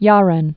(yärĕn)